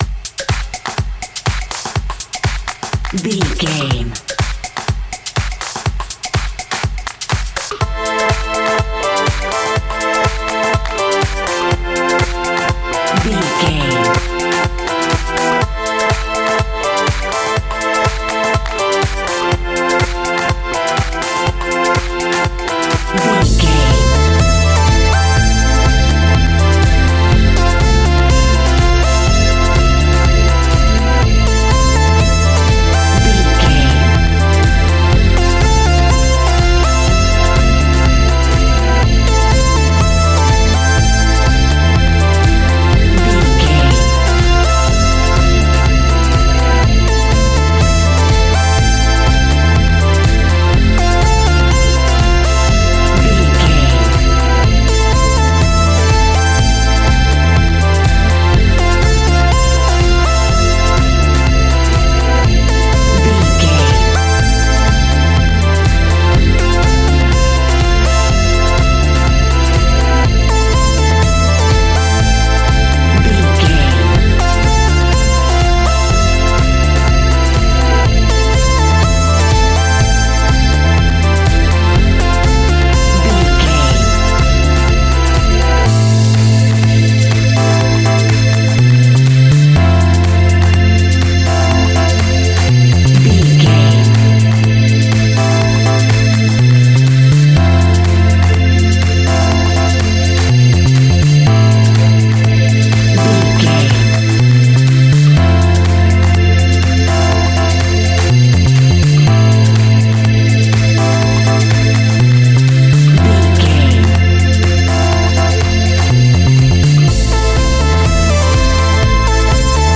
Aeolian/Minor
energetic
hypnotic
drum machine
disco house
electronic funk
upbeat
synth bass
electric piano
clavinet
horns